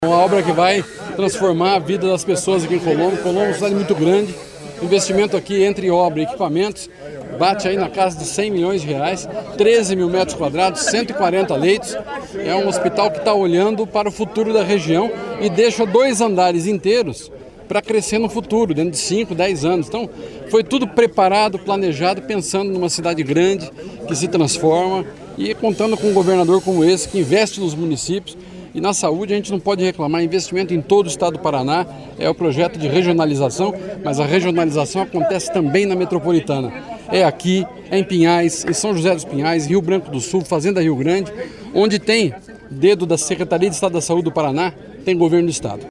Sonora do secretário da Saúde, Beto Preto, sobre a vistoria das obras do novo Hospital Geral de Colombo, na Grande Curitiba